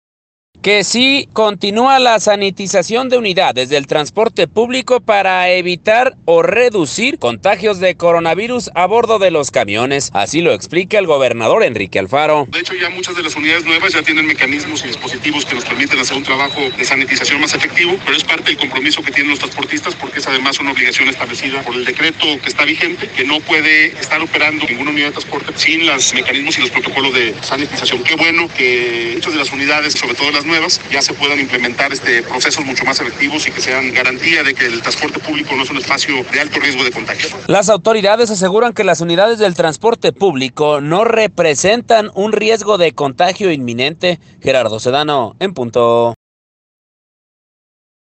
Que sí continúa la sanitización de unidades del Transporte Público para evitar o reducir contagios de coronavirus a bordo de los camiones, así lo explica el gobernador, Enrique Alfaro: